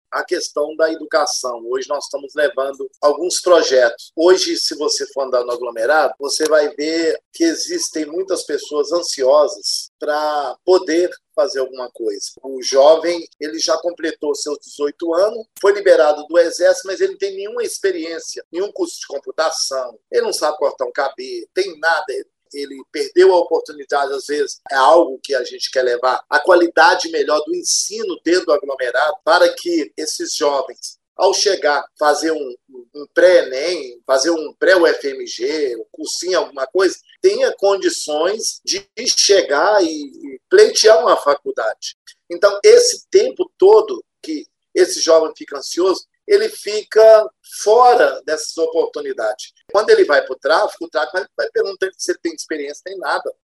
Vereador Gilson Guimarães, sobre os projetos voltados para a educação